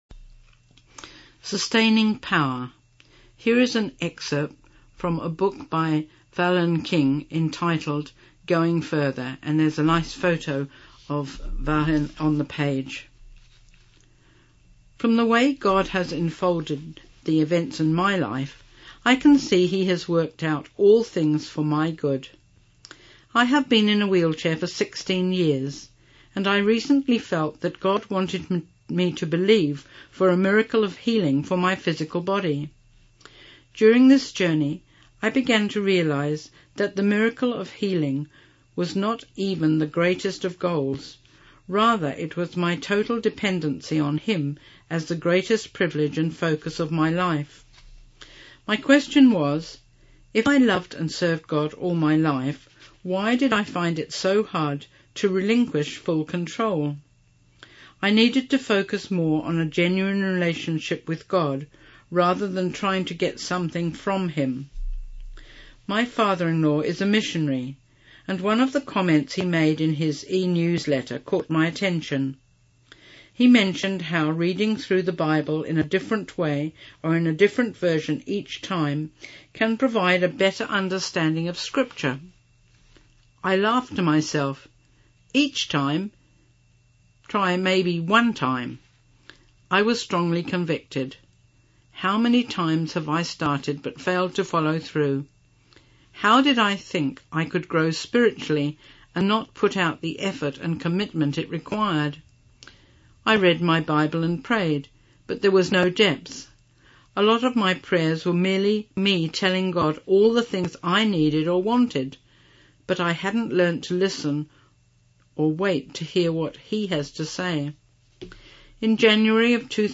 Genre: Speech..Released: 2016.